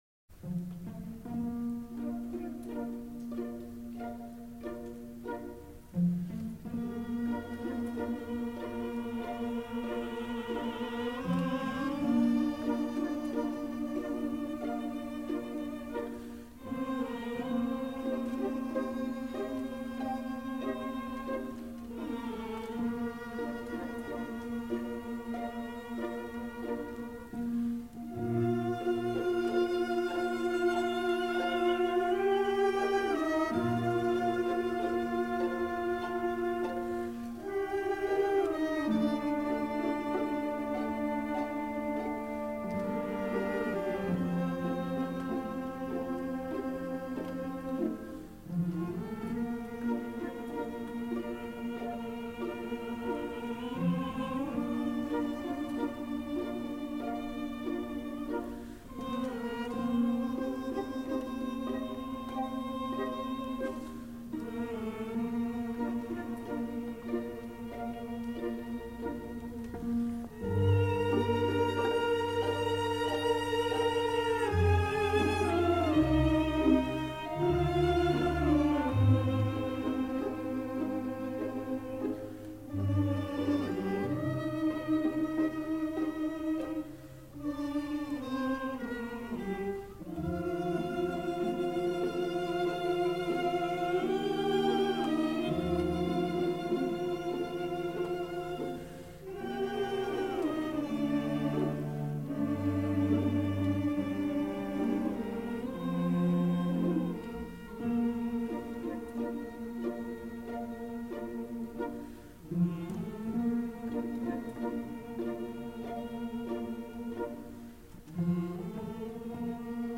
mélodie..douce
triste musique ,elle me fait pleurer,moi qui en ce moment ai beaucoup de peine...douce musique sensuelle